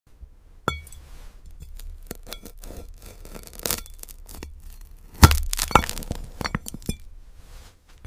Cutting a Glass Watermelon 🍉🔪 sound effects free download
Unreal ASMR in Ultra 8K